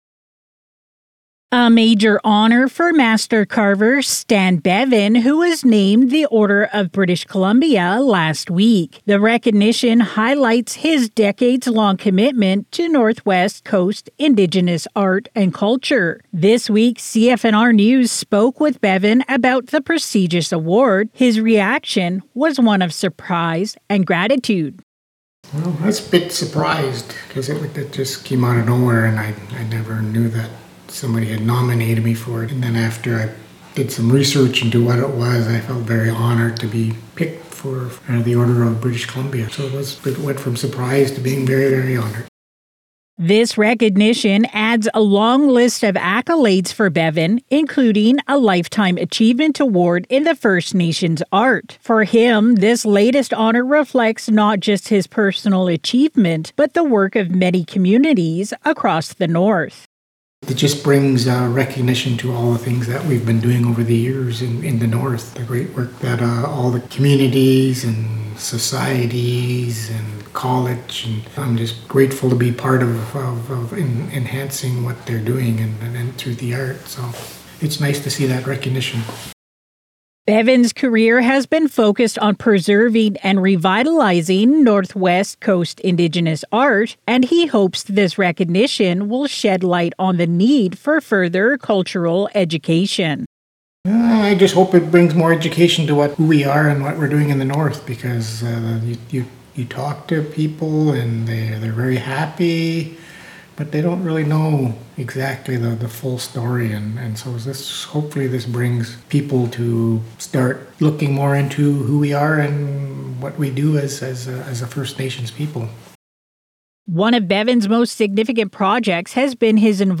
CFNR Interview